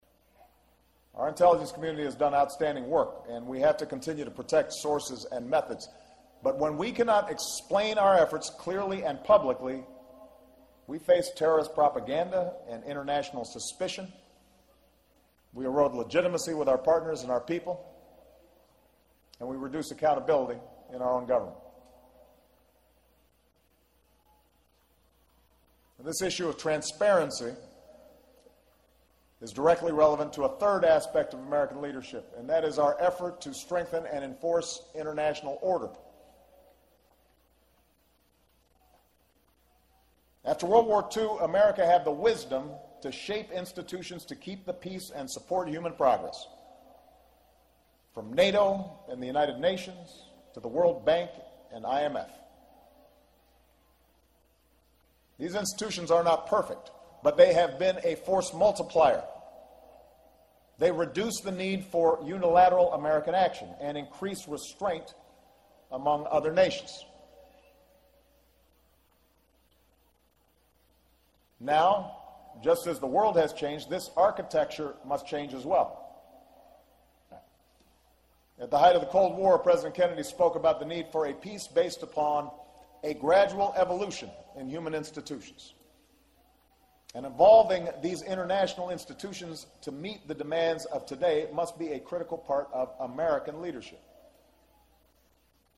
公众人物毕业演讲 第110期:奥巴马美国军事学院(13) 听力文件下载—在线英语听力室